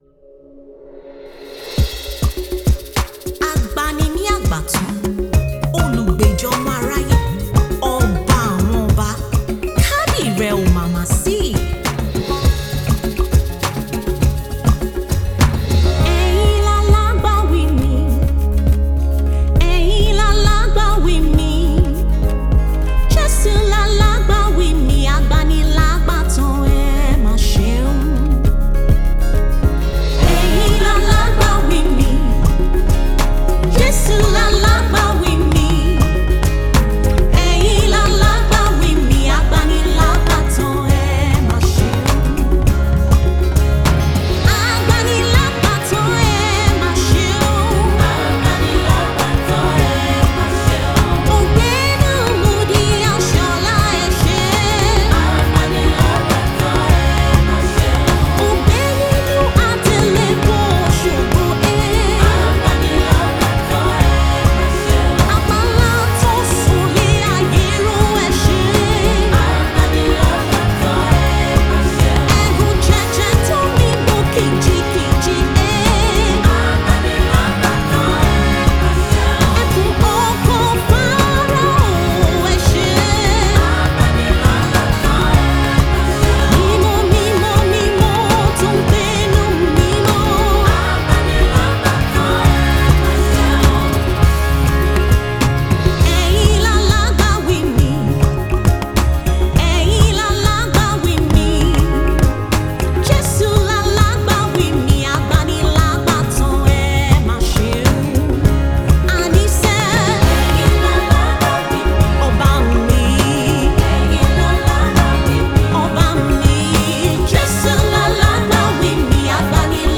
Nigerian UK-based gospel music minister